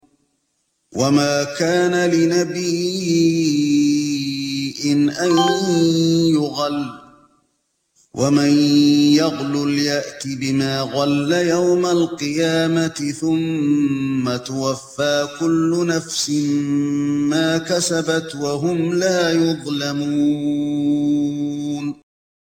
2- رواية ورش
أستمع للشيخ الحذيفي من هنا